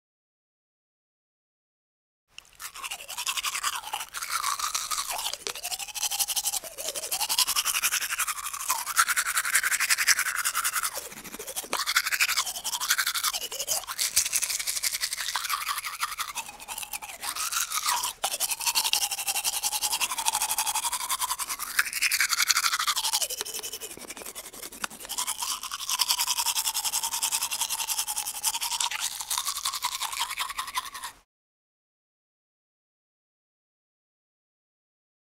دانلود آهنگ مسواک زدن 1 از افکت صوتی انسان و موجودات زنده
جلوه های صوتی
دانلود صدای مسواک زدن 1 از ساعد نیوز با لینک مستقیم و کیفیت بالا